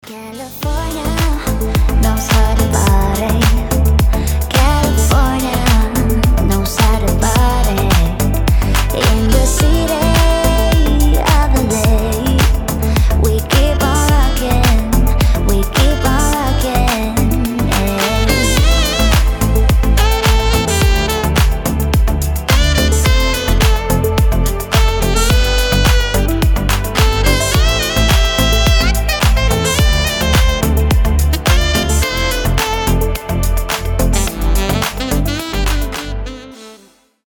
deep house
Саксофон
красивый женский голос
теплые
Chill